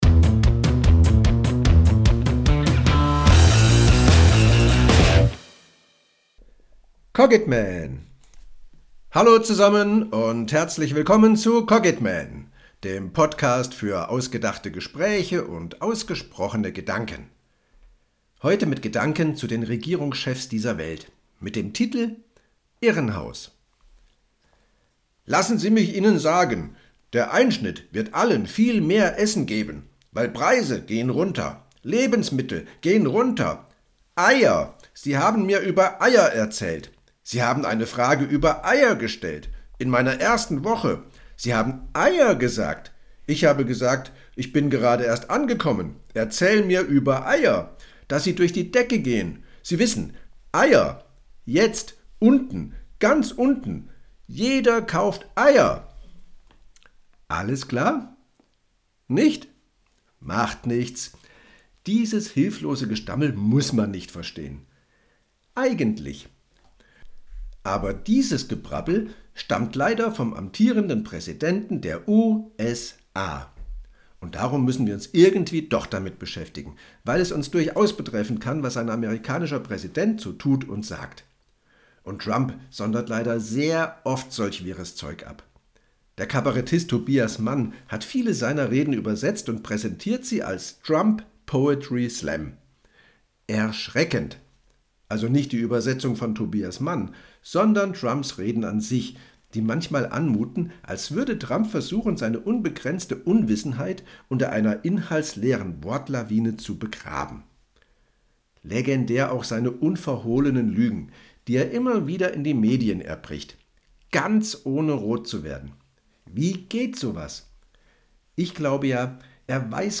Dialog_Irrenhaus.mp3